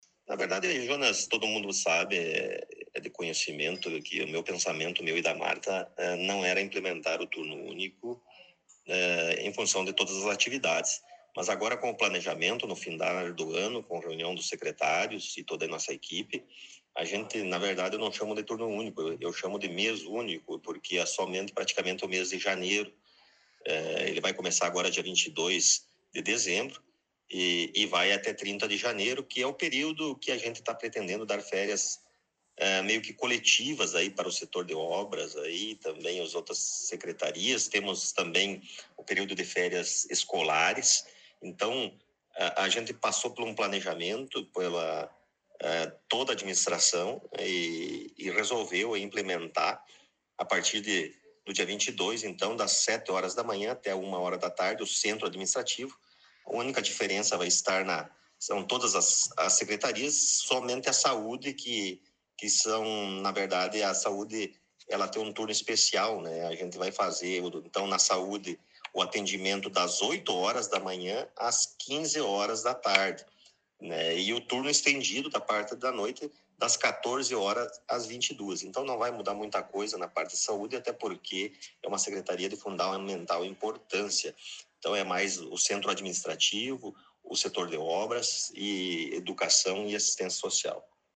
Prefeito Rodrigo Sartori concedeu entrevista
O Colorado em Foco teve a oportunidade de entrevistar mais uma vez o prefeito Rodrigo Sartori em seu gabinete.